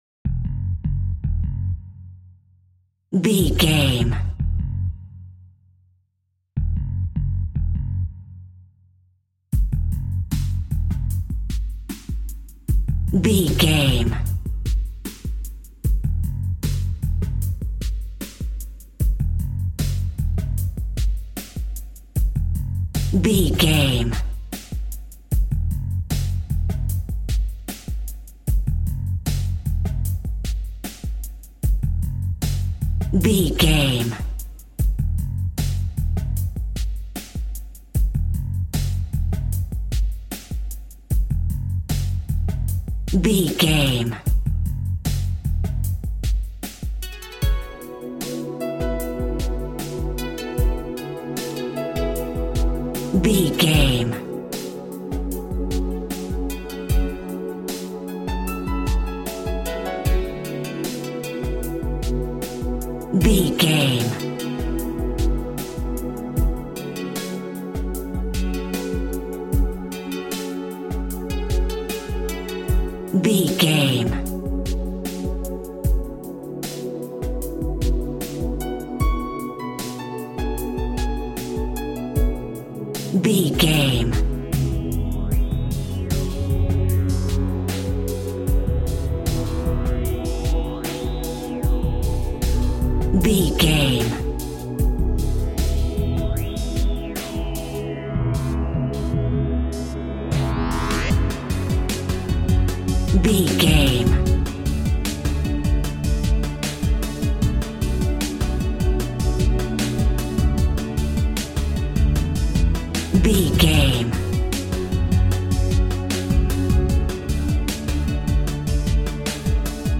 Aeolian/Minor
F#
Drum and bass
break beat
electronic
sub bass
synth